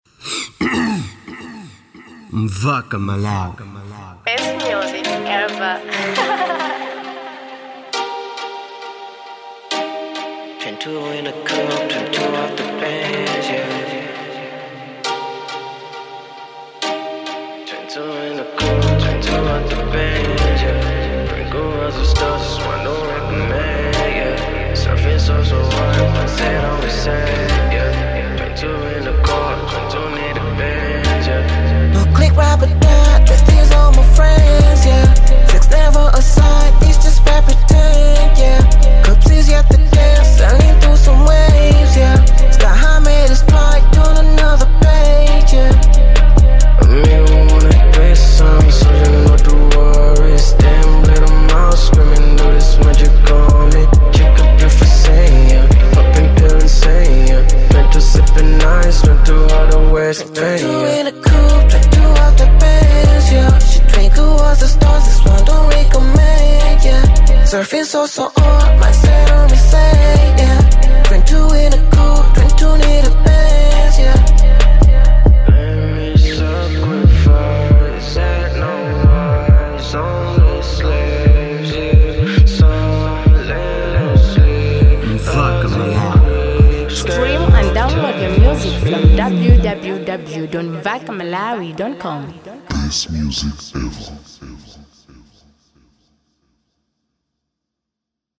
type: hip-hop